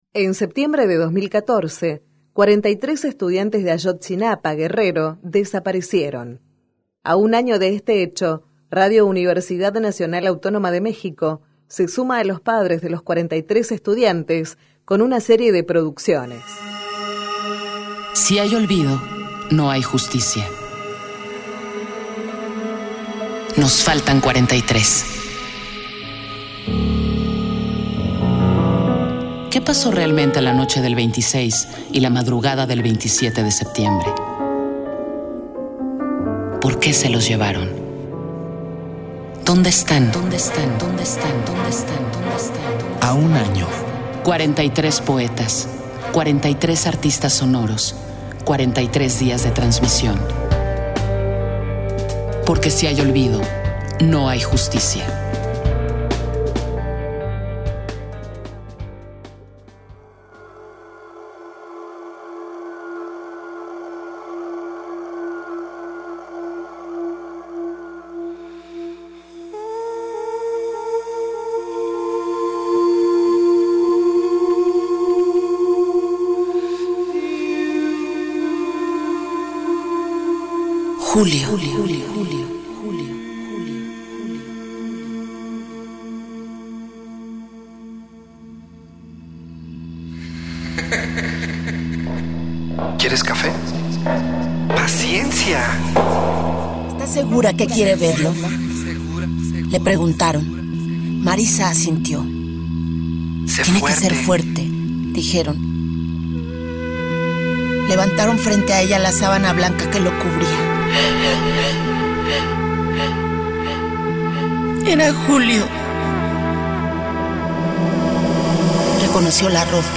A un año de los sucesos, la producción de Radio UNAM reúne distintas personalidades del ámbito radiofónico que, entre poesía, música y ambientes sonoros, rescatan la memoria viva del sonado caso de los estudiantes desaparecidos de la Escuela Normal Rural de Ayotzinapa.